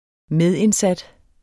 Udtale [ ˈmεðenˌsad ]